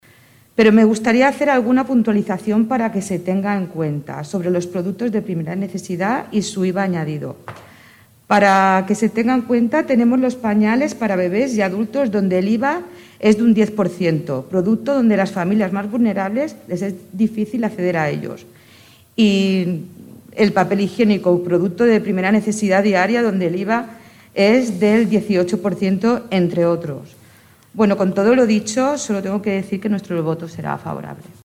Des de Ciutadans, Miriam Rocabruna reforça la necessitat de conscienciar sobre aquesta gran molèstia i per això voten a favor de la moció. Sobre el cas de l’IVA, la regidora puntualitza: